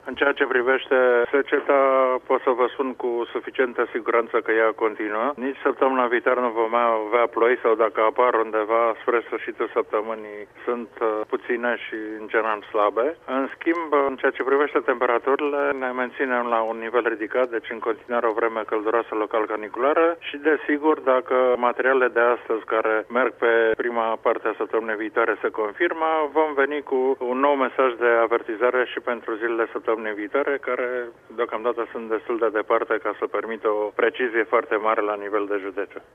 Valul de căldură va continua să ne afecteze şi săptămâna viitoare, a declarat, pentru Radio România Actualităţi